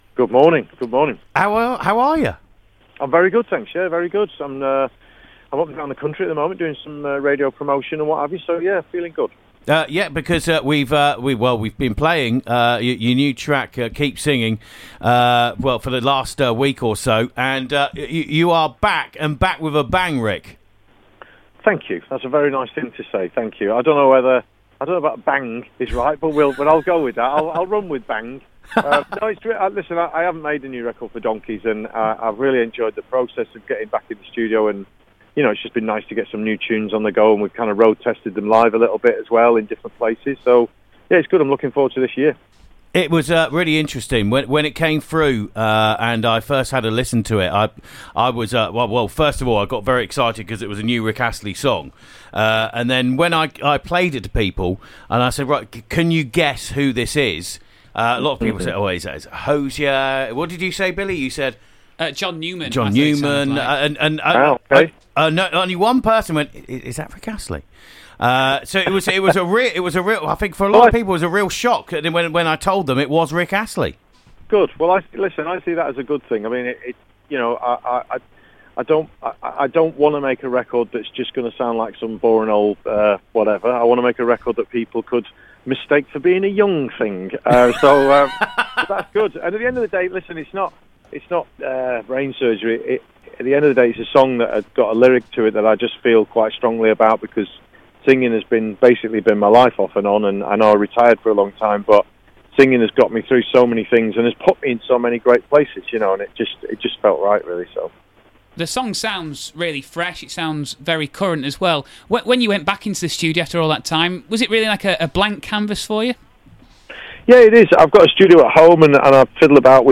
Rick Astley on Radio Yorkshire!!!!